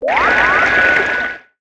water6.wav